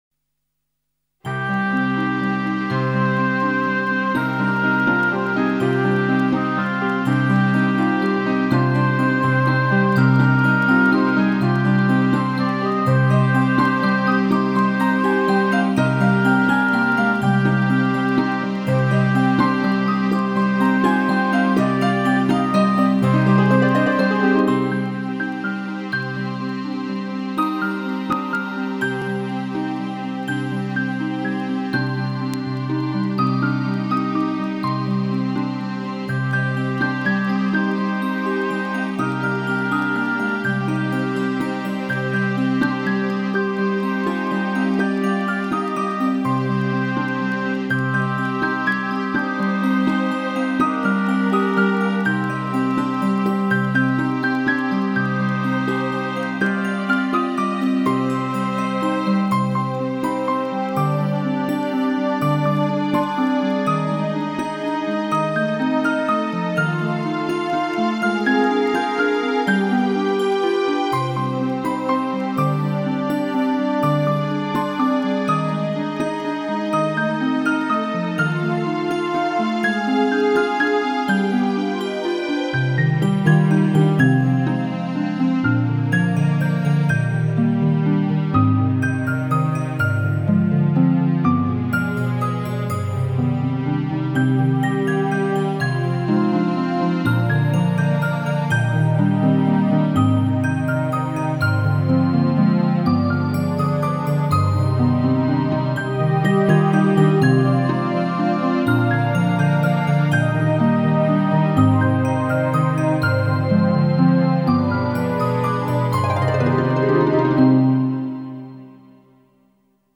دریافت فایل موسیقی بدون کلام کلیک کنید.